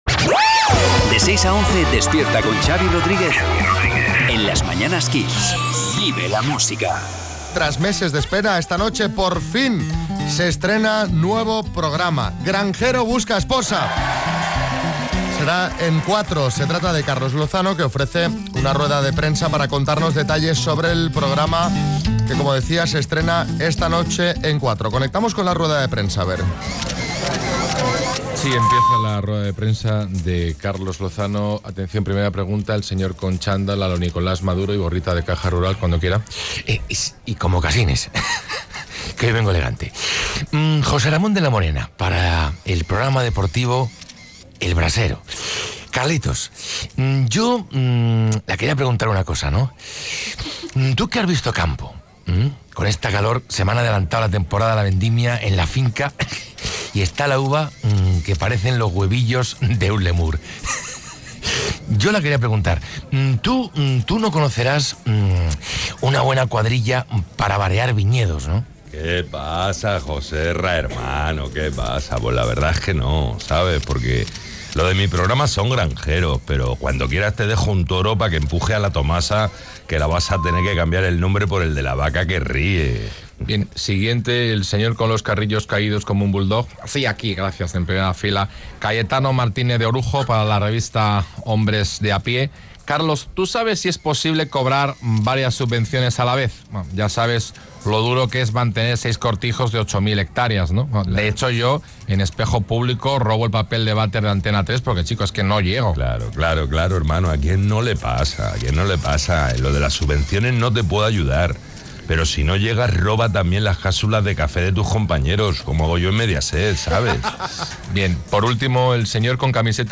El presentador estrena programa en Cuatro y ofrece una rueda de prensa.
Carlos Lozano estrena «Granjero busca esposa» y nosotros asistimos a la rueda de prensa de presentación donde el propio Carlos nos adelanta detalles de lo que vamos a ver a partir de esta noche.